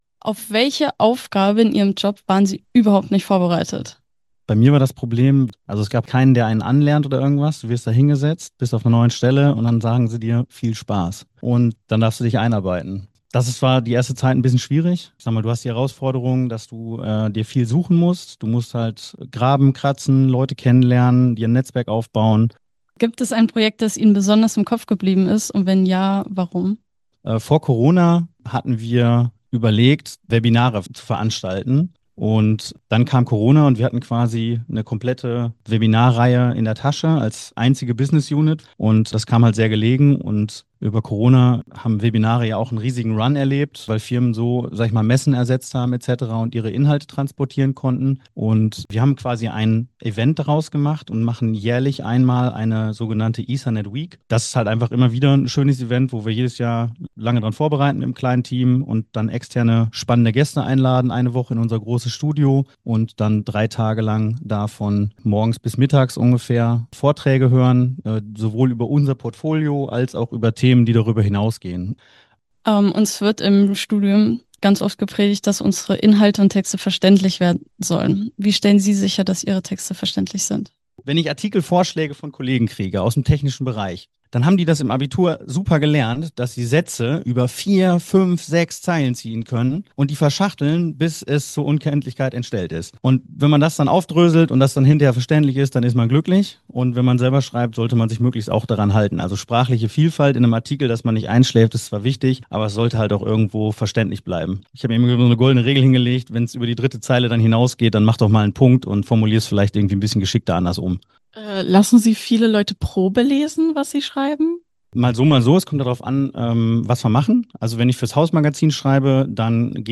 Technische Redakteur*innen im Gespräch